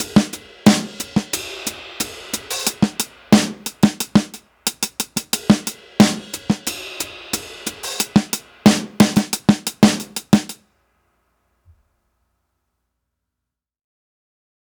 Unison Jazz - 8 - 90bpm - Tops.wav